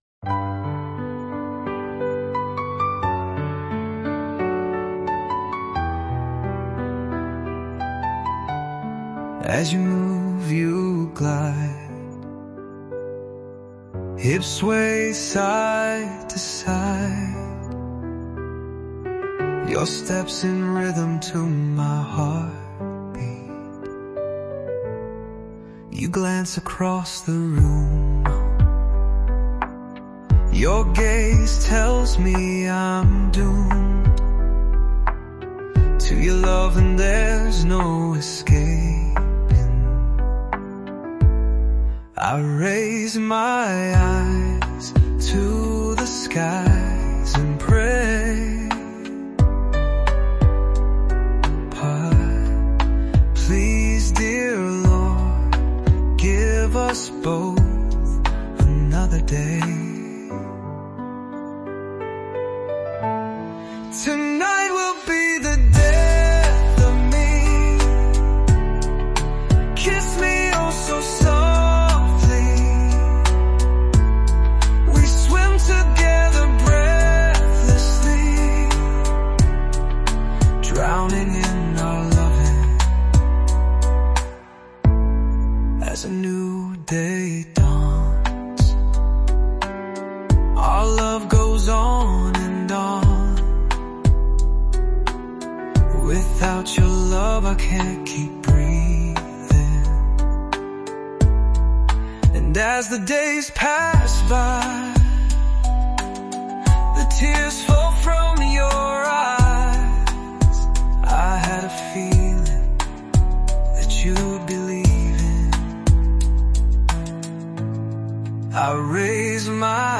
revised with music and vocals June 26,2025